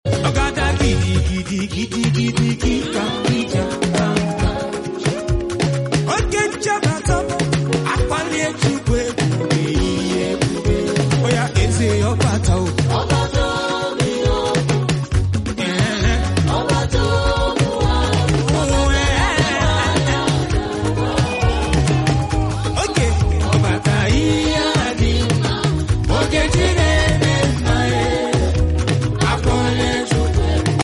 Never goes wrong on Alujo 💃🏻